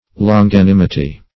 Longanimity \Lon`ga*nim"i*ty\, n. [L. longanimitas; longus long